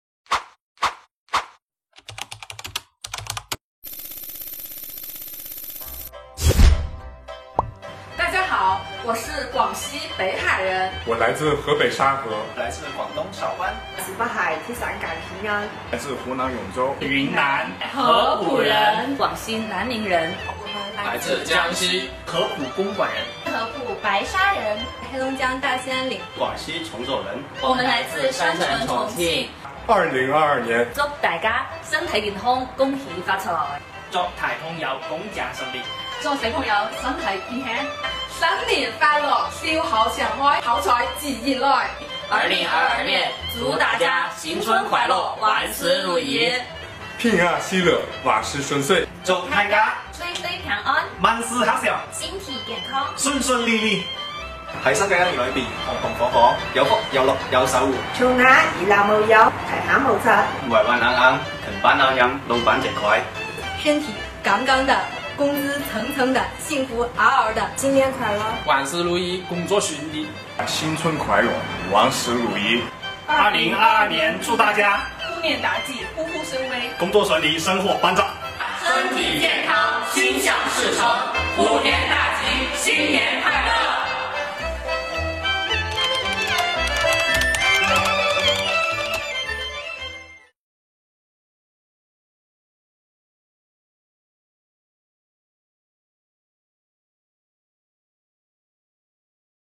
北海税务人给大家拜年啦！